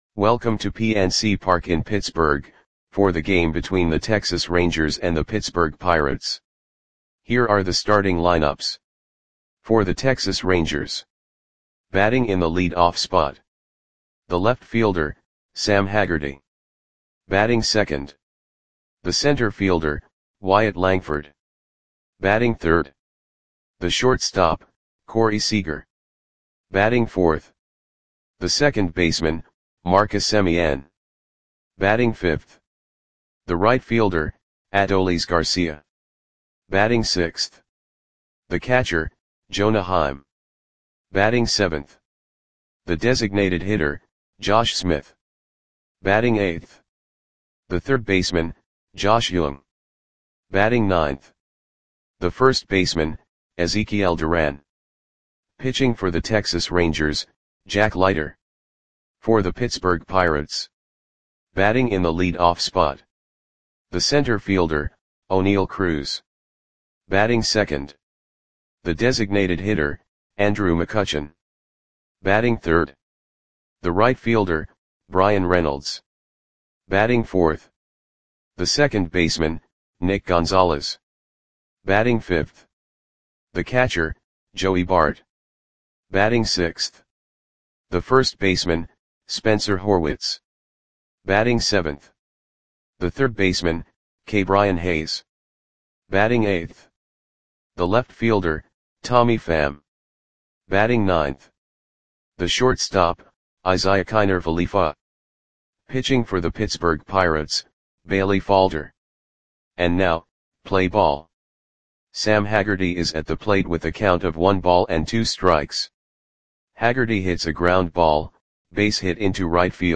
Audio Play-by-Play for Pittsburgh Pirates on June 22, 2025
Click the button below to listen to the audio play-by-play.